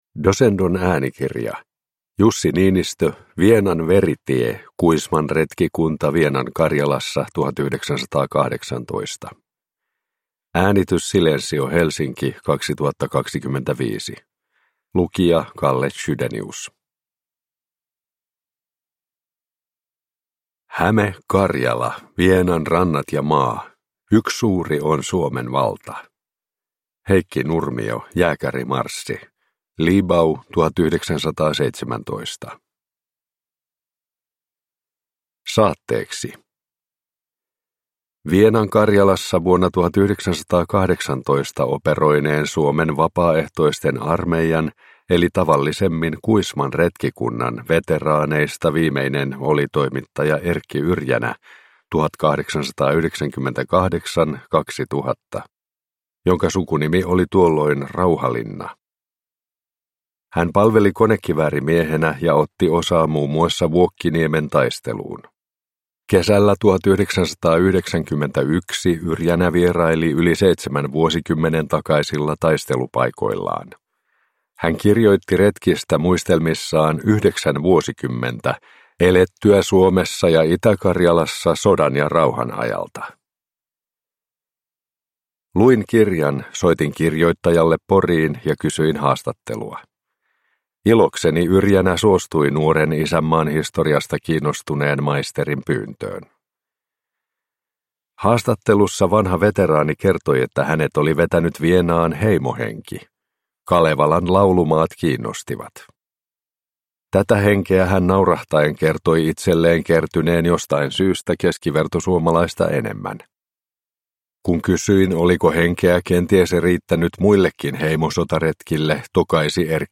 Vienan veritie – Ljudbok